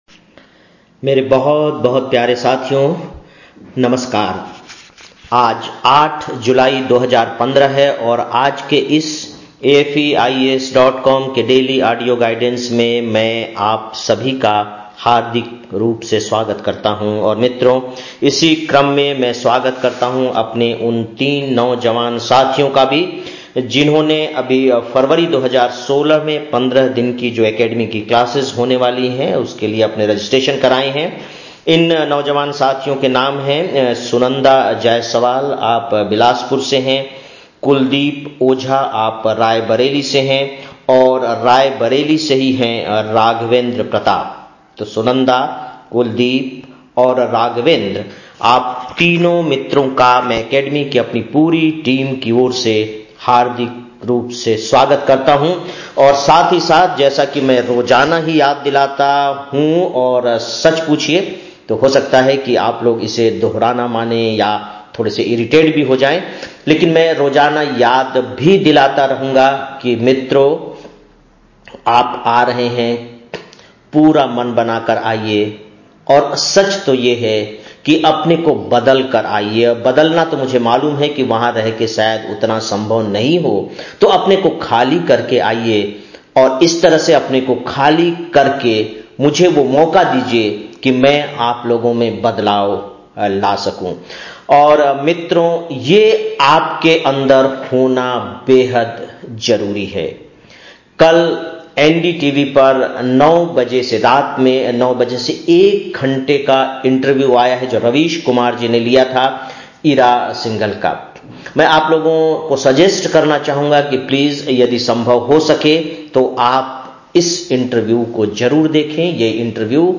08-07-15 (Daily Audio Lecture) - AFEIAS